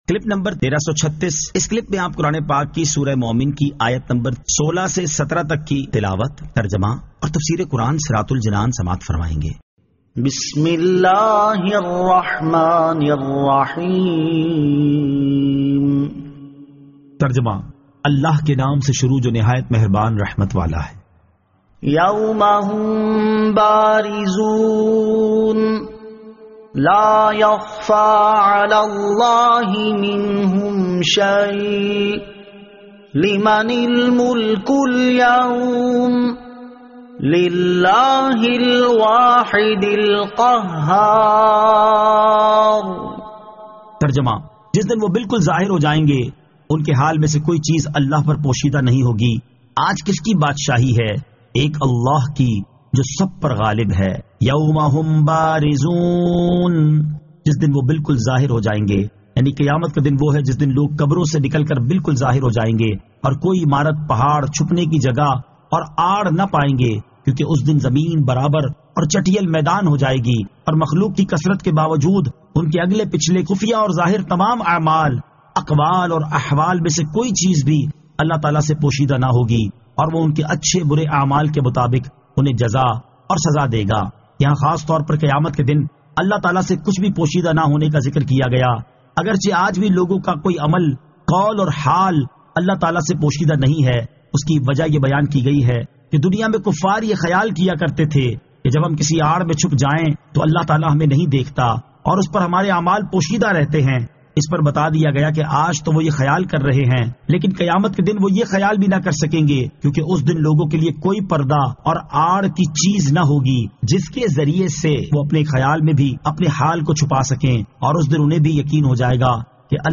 Surah Al-Mu'min 16 To 17 Tilawat , Tarjama , Tafseer